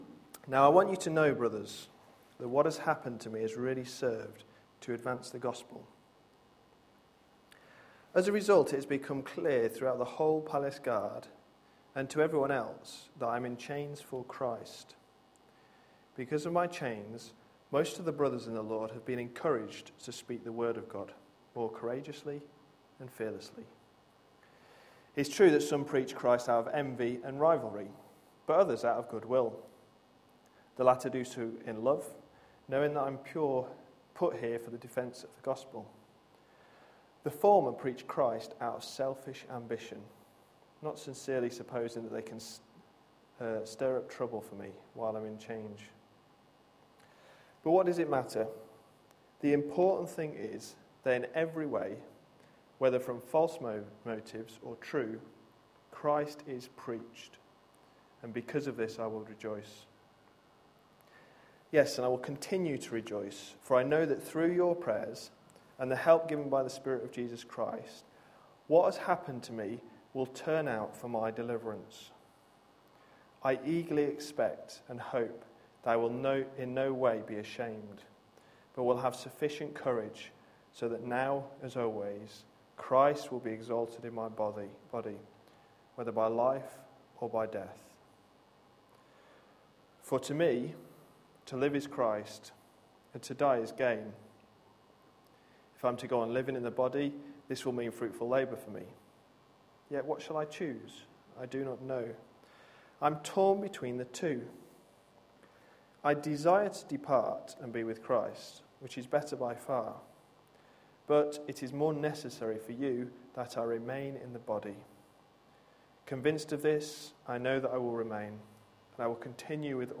A sermon preached on 29th July, 2012, as part of our Philippians series.